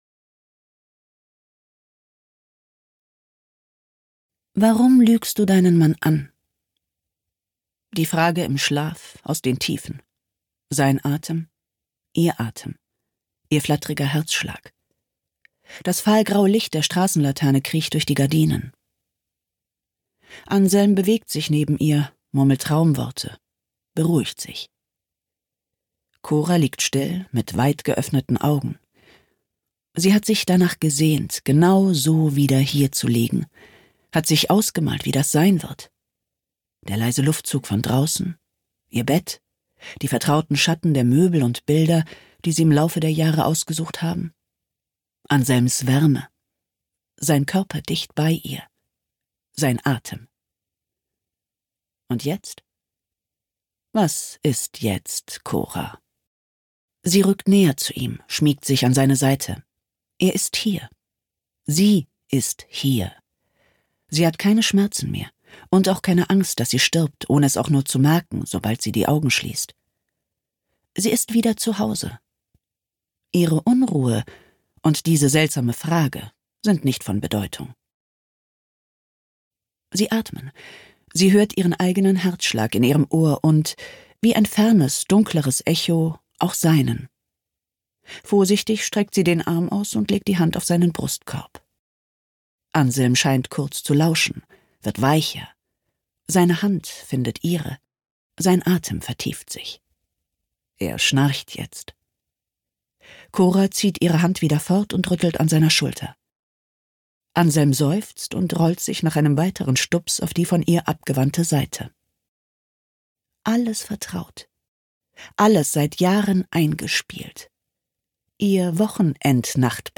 Ein lebenskluges Hörbuch über eine Ehe im Umbruch, zweite Chancen und Selbstfindung.
Gekürzt Autorisierte, d.h. von Autor:innen und / oder Verlagen freigegebene, bearbeitete Fassung.